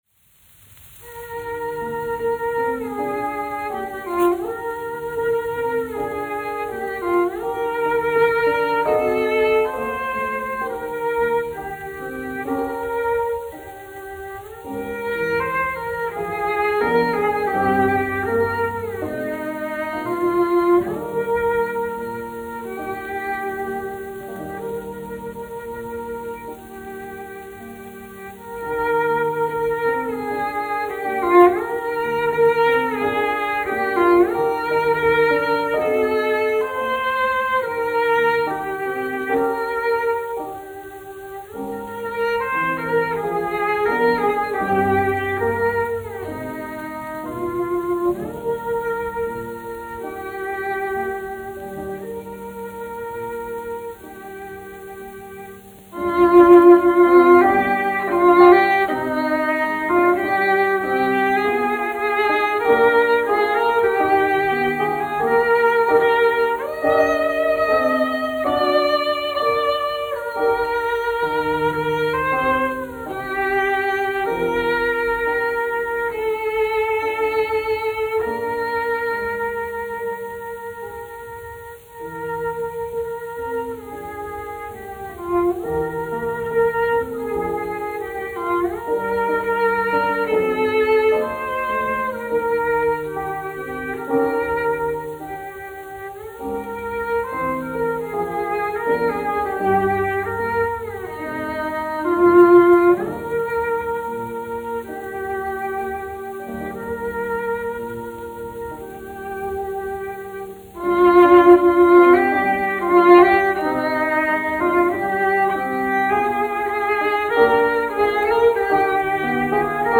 1 skpl. : analogs, 78 apgr/min, mono ; 25 cm
Čella un klavieru mūzika
Latvijas vēsturiskie šellaka skaņuplašu ieraksti (Kolekcija)